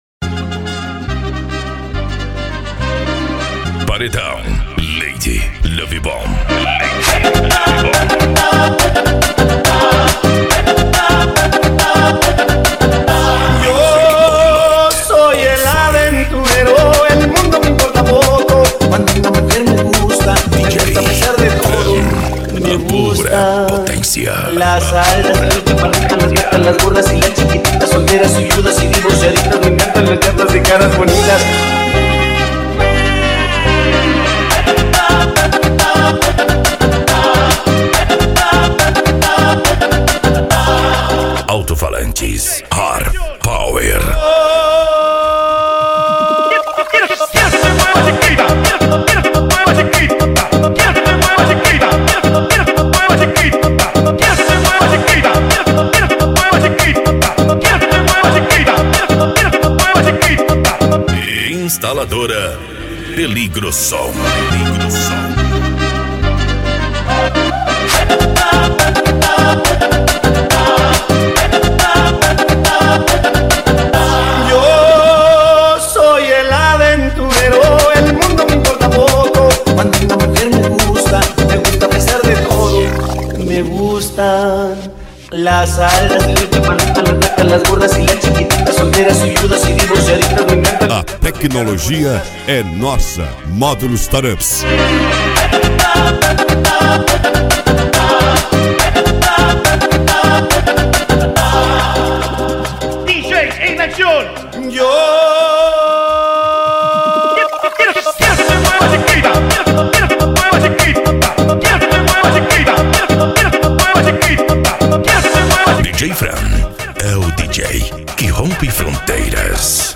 Bass
Eletronica
Remix